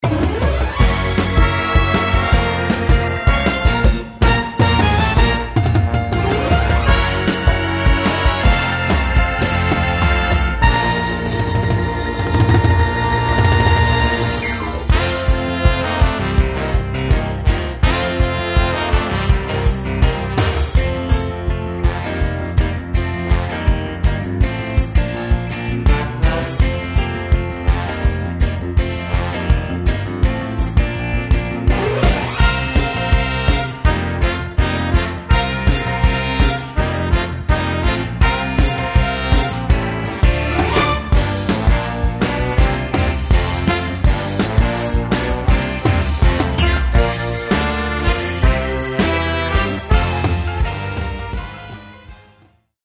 This is a professional performance track
Instrumental
Orchestra